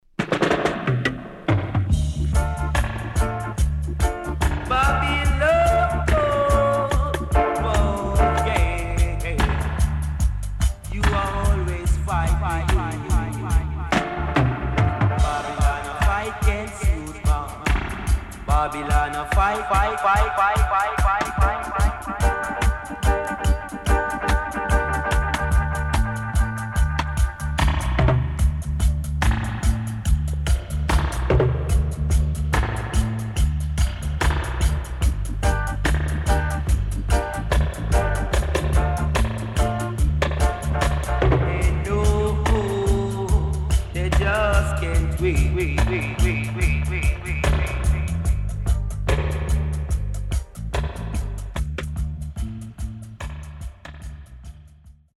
HOME > REISSUE [REGGAE / ROOTS]